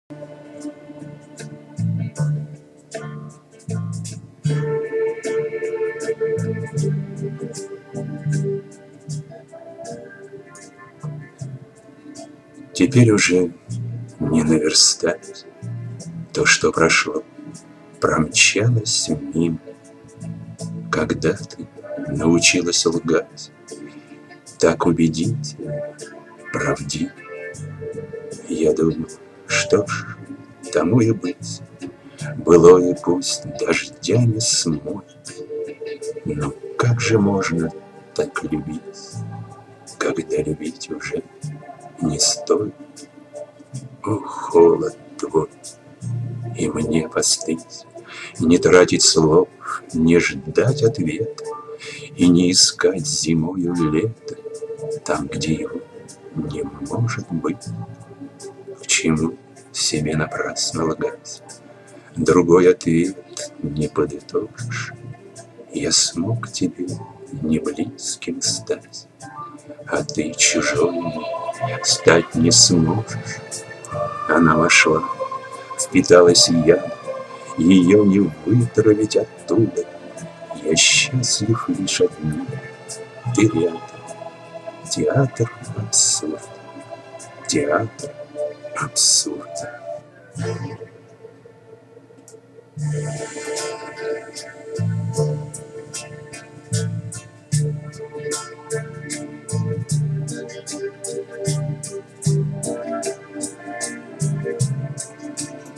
Сижу,слушаю и улыбаюсь.Нравится мне ваша декламация, несмотря на "плавающее"муз.сопровождение))
которой я пишу. очень несовершенна, глотает буквы, а то и слоги, тянет звук и даже меняет скорость. надеюсь найти что-то получше.